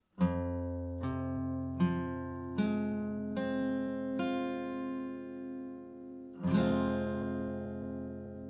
Der f-Moll-Akkord besteht aus den drei Tönen: F, As und C, die auch als Dreiklang bezeichnet werden.
f-Moll (Barré, E-Saite)
F-Moll-Akkord, Gitarre
F-Moll-Barre-E.mp3